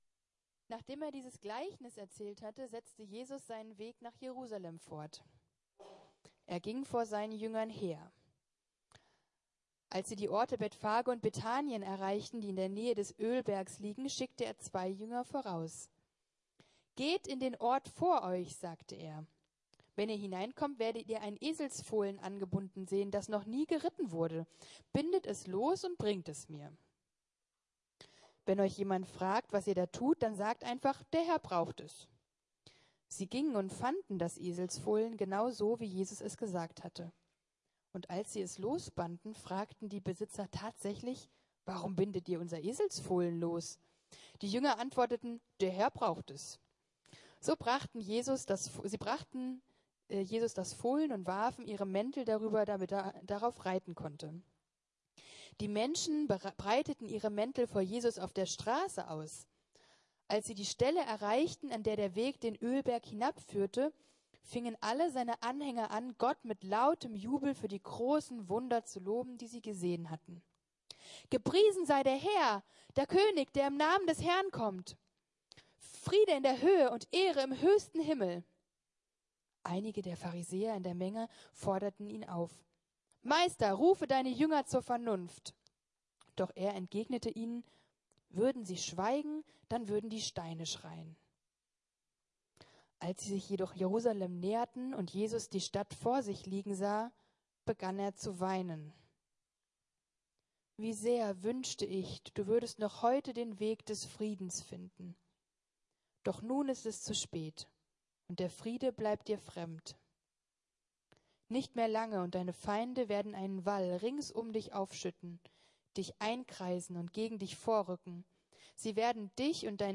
Und Jesus weinte ~ Predigten der LUKAS GEMEINDE Podcast